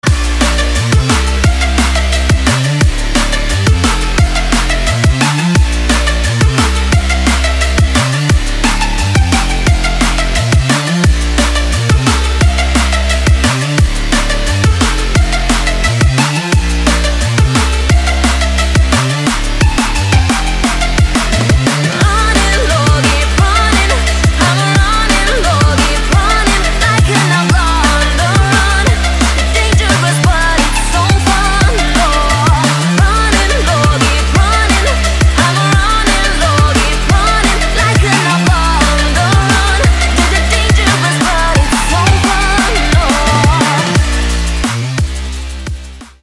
• Качество: 320, Stereo
громкие
женский вокал
Electronic
EDM
drum n bass